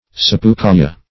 Search Result for " sapucaia" : The Collaborative International Dictionary of English v.0.48: Sapucaia \Sap`u*ca"ia\ (?; Pg.